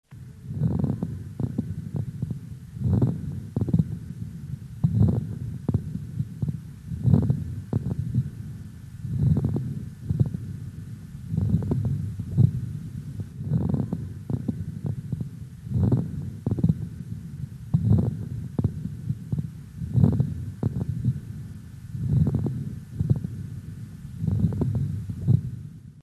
Звук патологического бронхиального дыхания при правосторонней крупозной пневмонии первой стадии